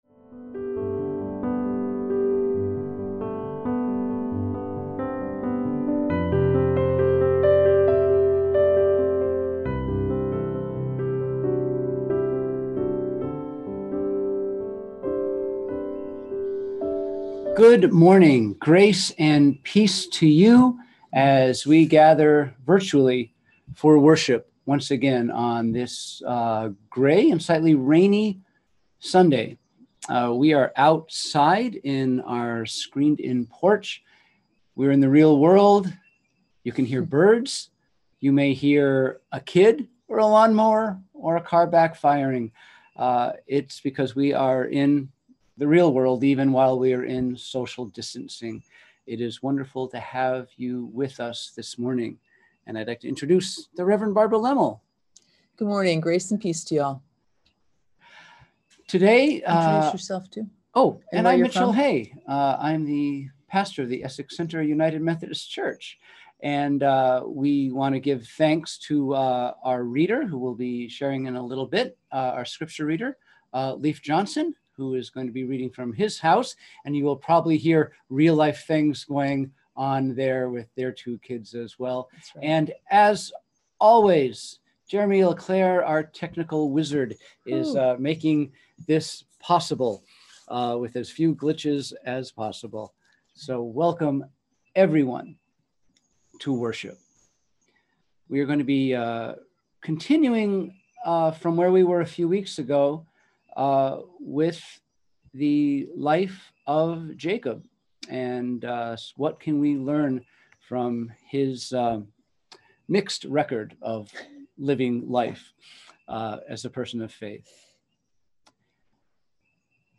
We held virtual worship on Sunday, August 2, 2020 at 10am.